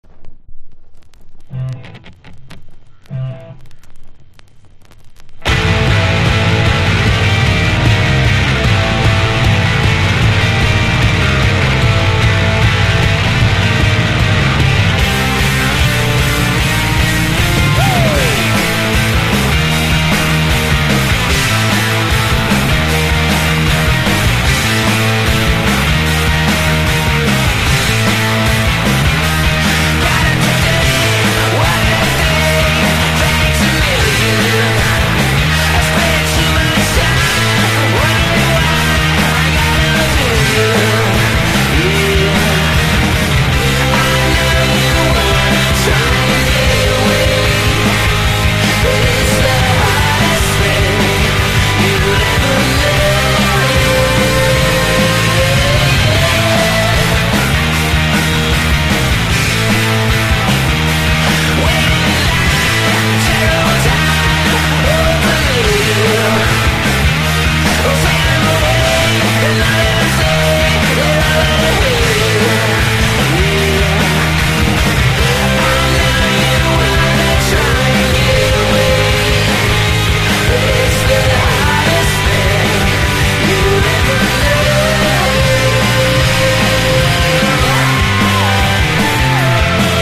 90`Sを感じさせるダイナミックな演奏にざらついたヴォーカルが良いです！！